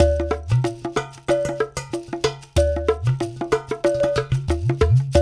93 -UDU 03R.wav